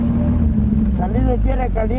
Fiesta de Santiago Tuxtla : investigación previa